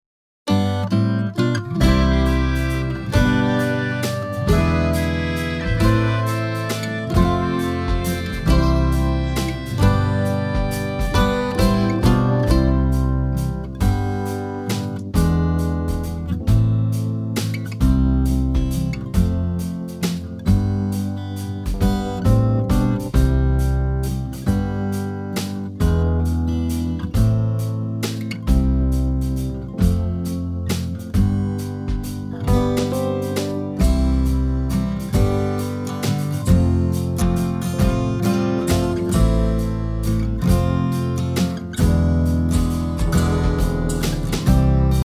Listen to a sample of the sing-along track.
2. Mp3 Instrumental Sing Along track;